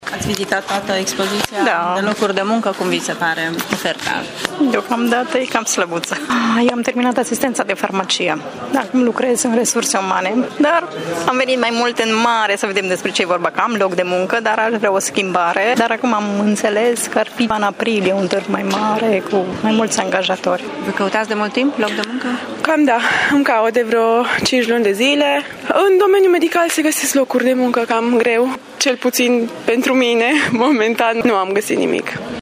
Târgumureșenii nu au fost foarte mulțumiți de oferta de locuri de muncă: